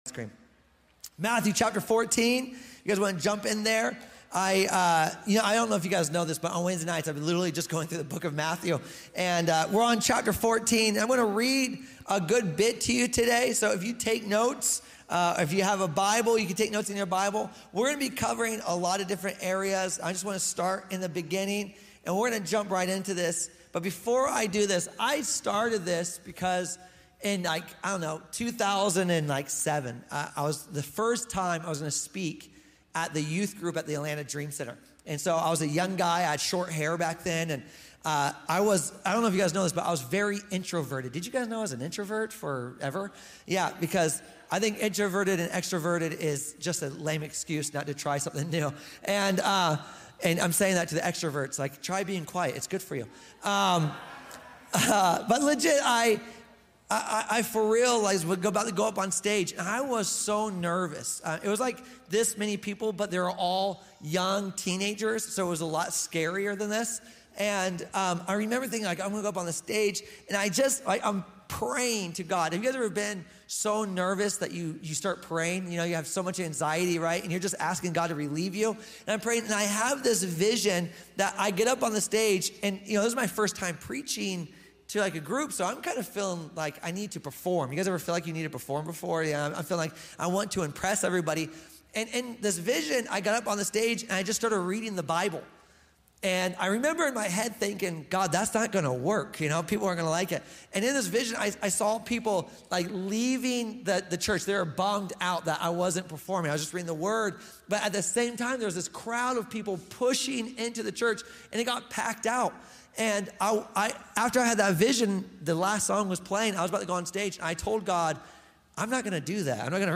Wednesday Livestream